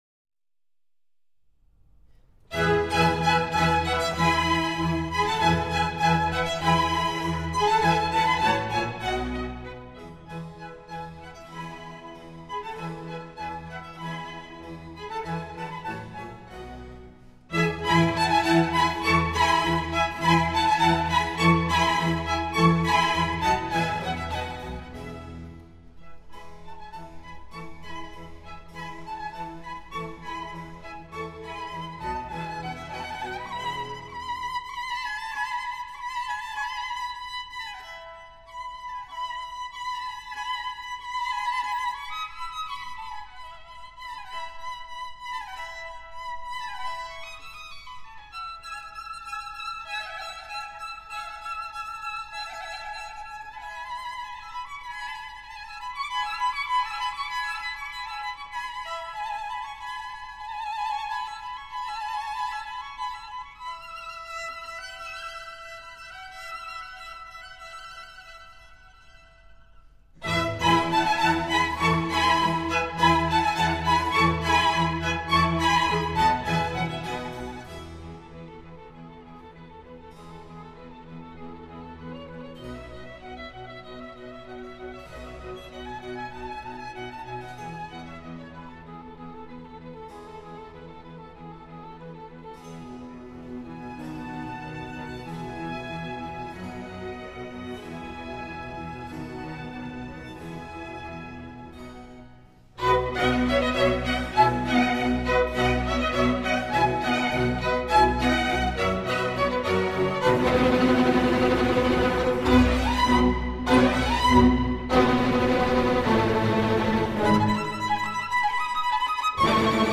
春 E大调 共3个乐章：